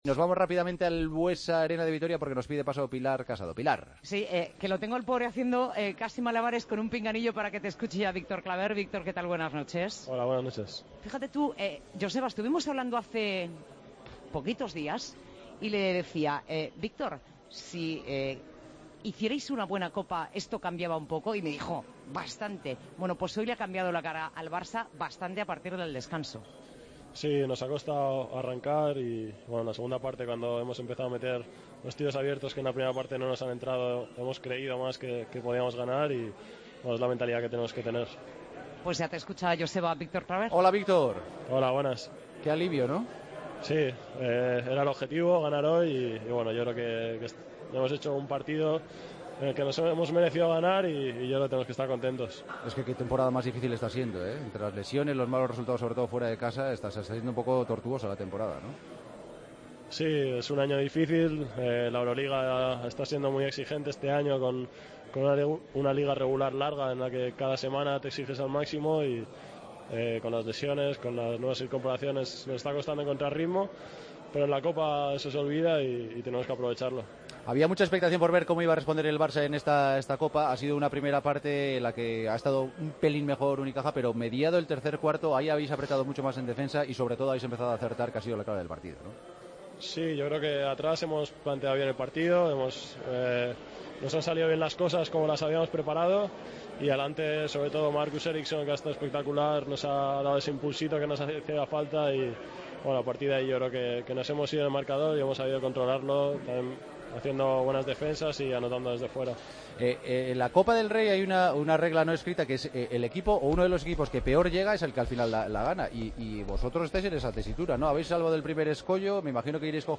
Hablamos con el jugador del Barcelona tras ganar a Unicaja: "Nos ha costado entrar en el encuentro y cuando han entrado los tiros hemos empezado a creer.